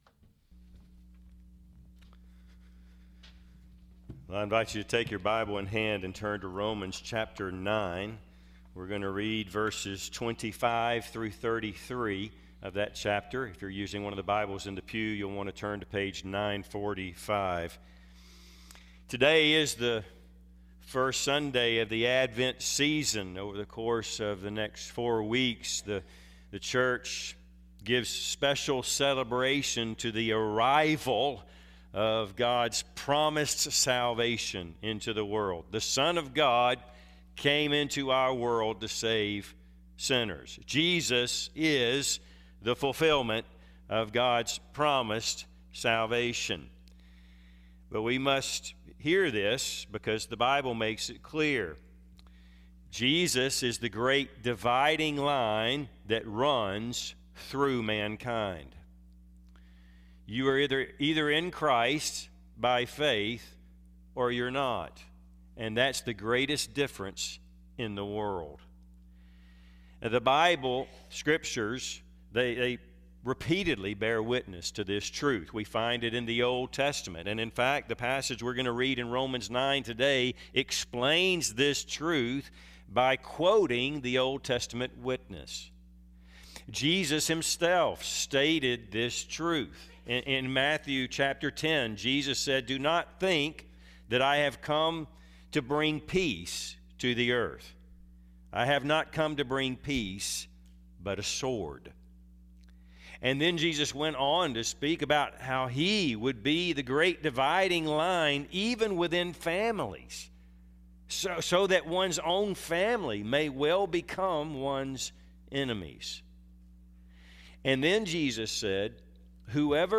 Passage: Romans 9:25-33 Service Type: Sunday AM